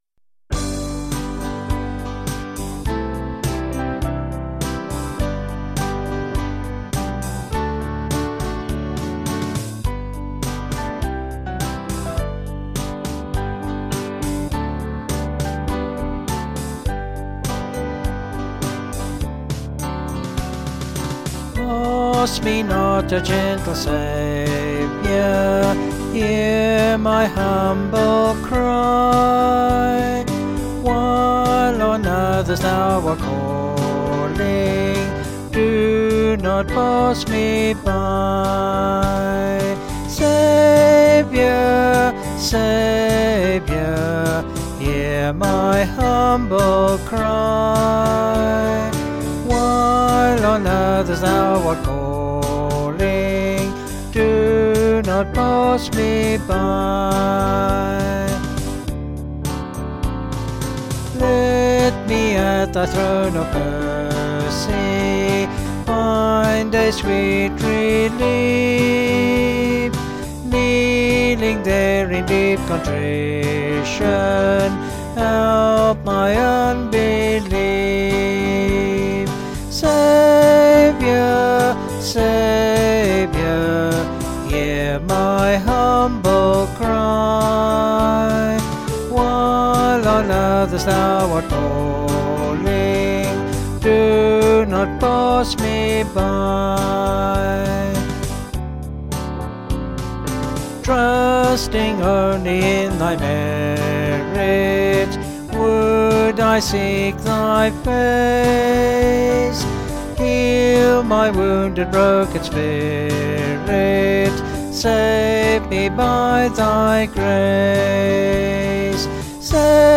Vocals and Band   263.6kb Sung Lyrics